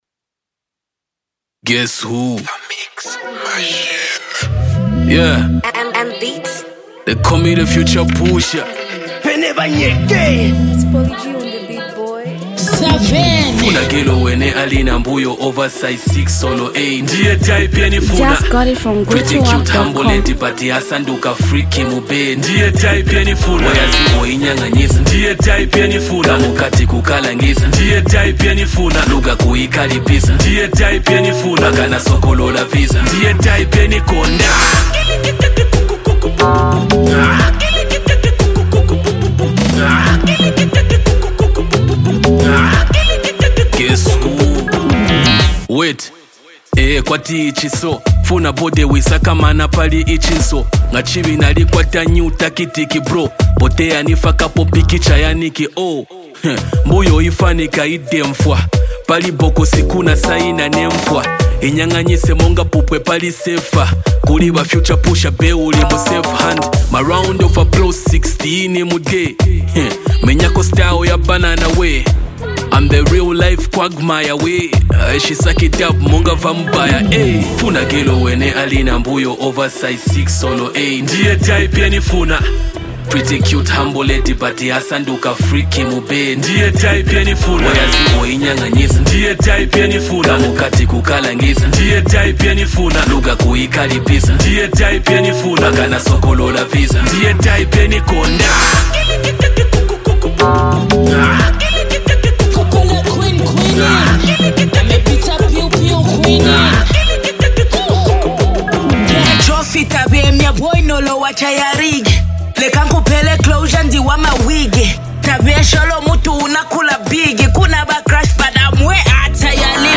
Zambian Mp3 Music
rapper
buzzing street record